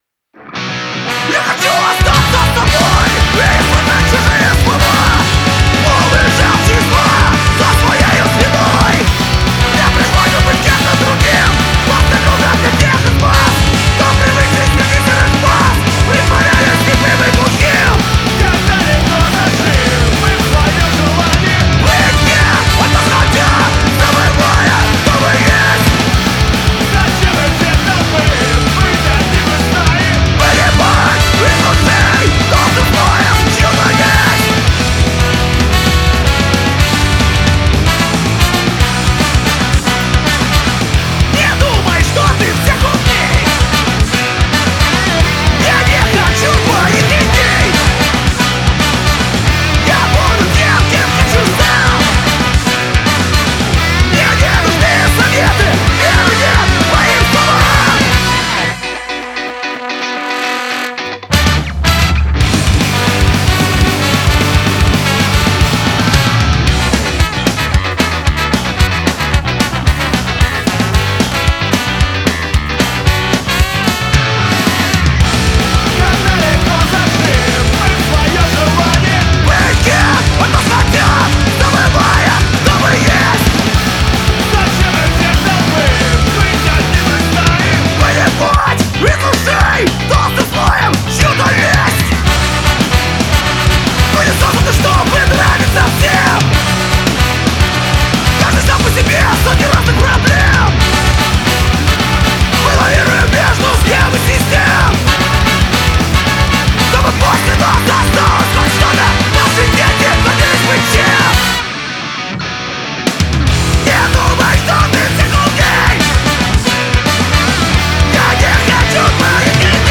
{SkaCore}